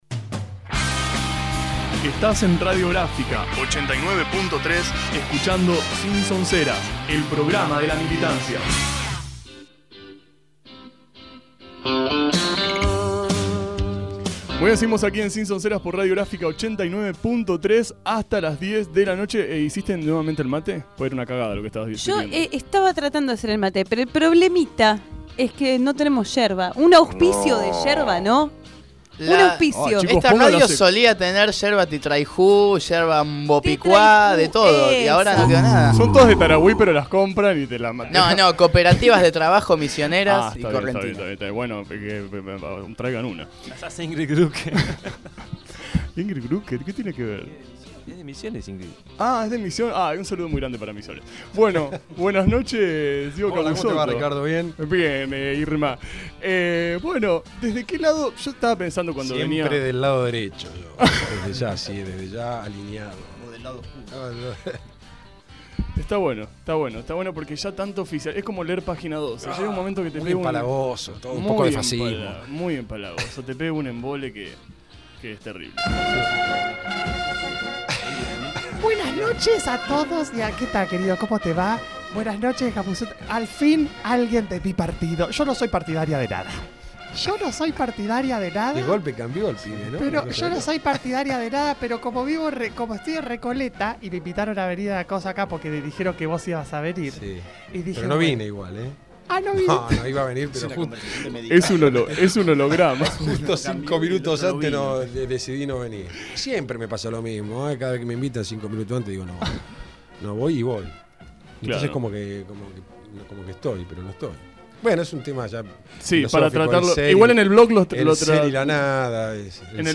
En seis fragmentos la entrevista completa para disfrutarla.